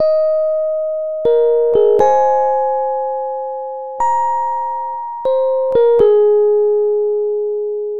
Little Lullaby Eb 120.wav